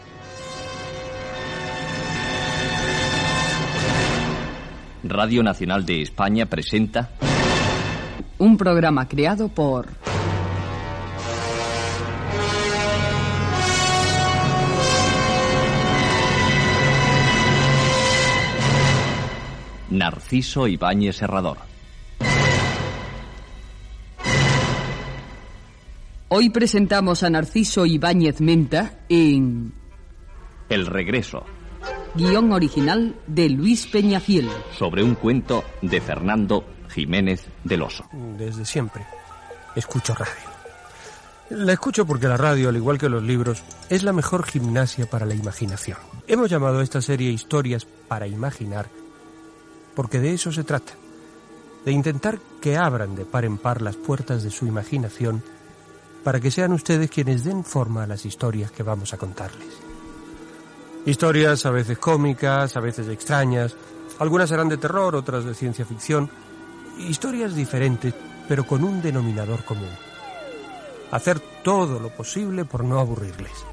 Careta del programa. Espai "El Regreso" versionant una obra de Fernando Jiménez del Oso. Explicació de Chicho Ibáñez Serrador
Ficció